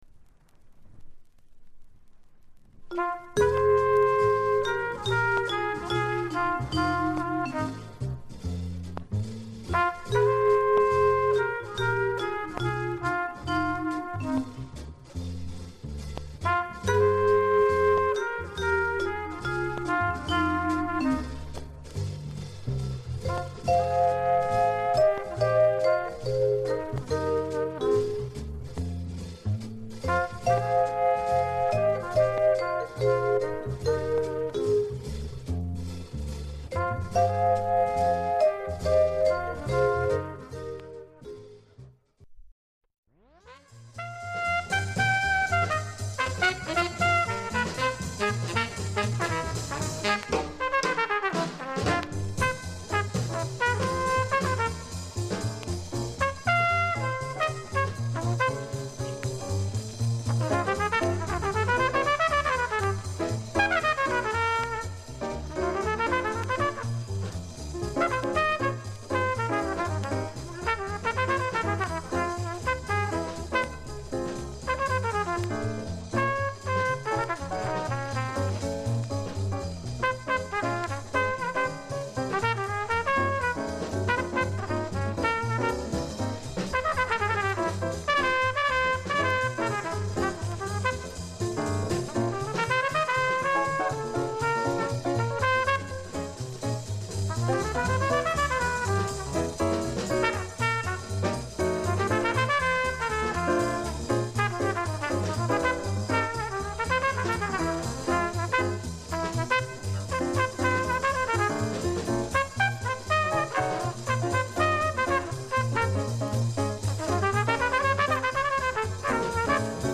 現物の試聴（上記）できます。音質目安にどうぞ ほかクリアで音質良好全曲試聴済み。
DG,RVG刻印両面 ◆ＵＳＡ盤 NJオリジナル MONO